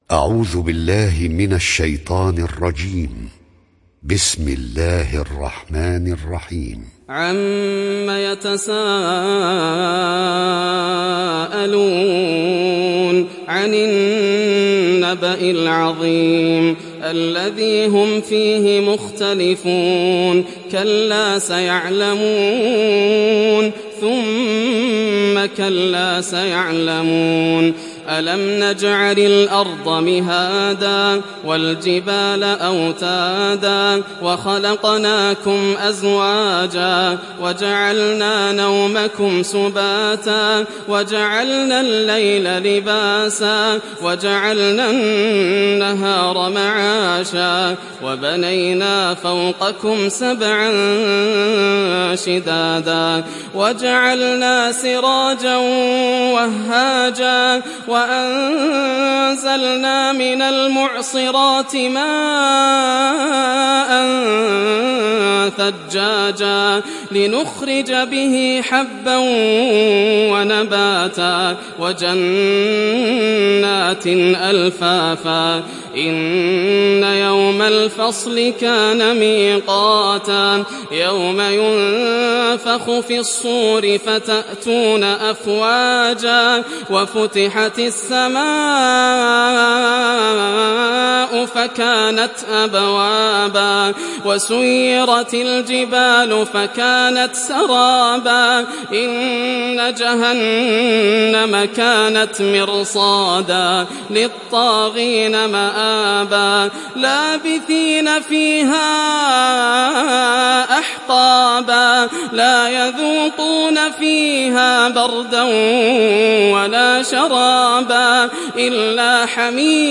Surat An Naba mp3 Download Yasser Al Dosari (Riwayat Hafs)